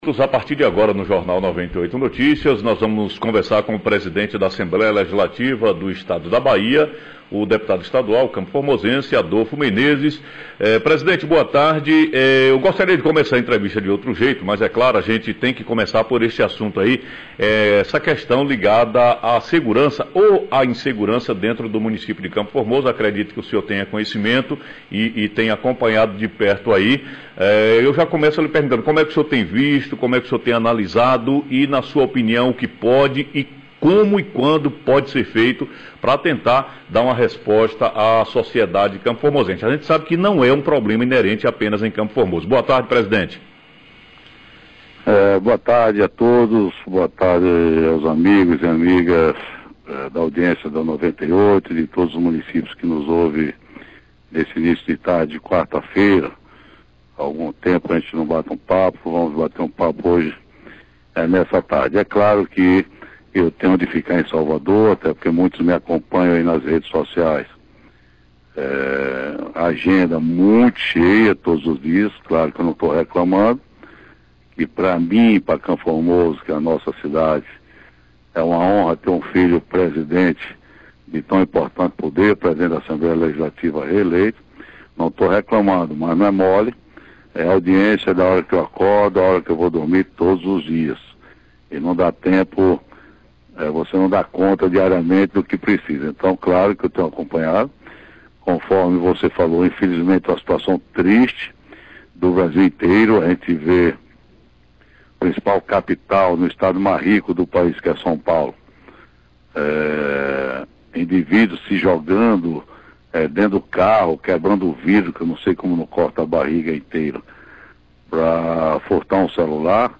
Entrevista- Presidente da ALBA, Adolfo Menezes